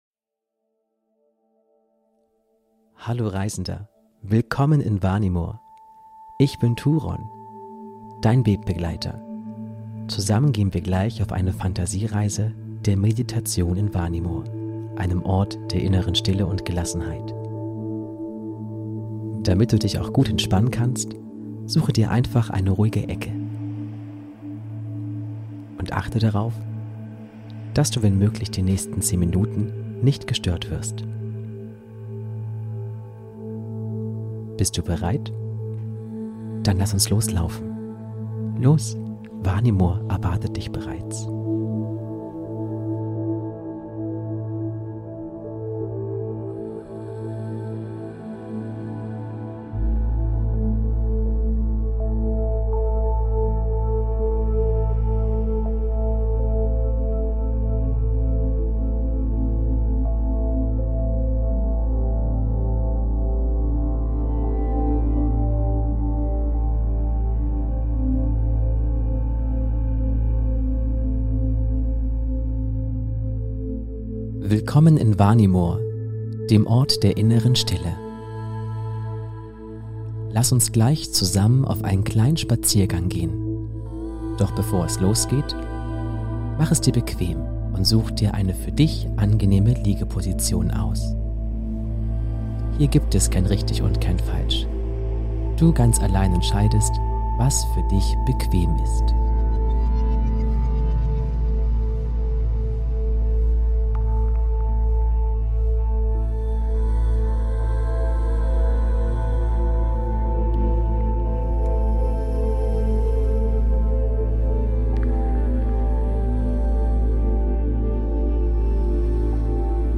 Meditation: Vanimor - eine Reise zur inneren Stille//Entspannungsreise ~ Vanimor - Seele des Friedens Podcast